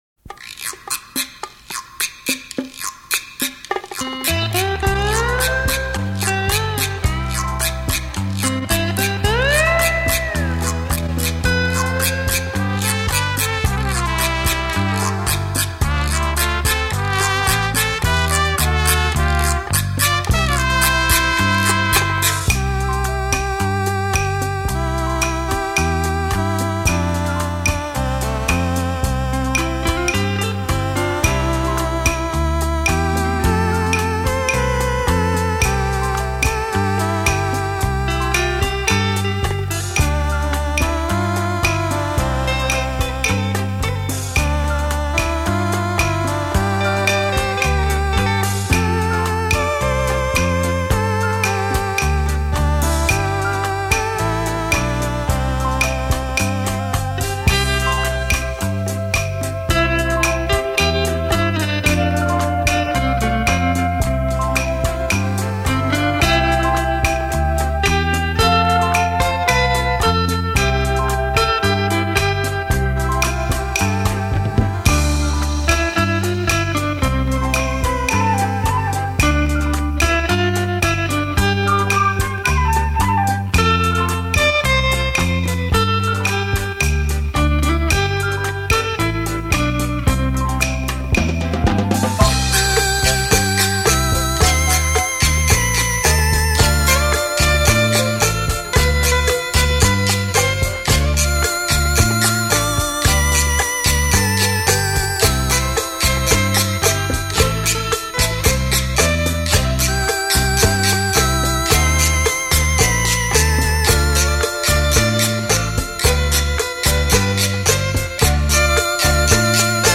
柔美恒久的音乐，带给您昔日美好回忆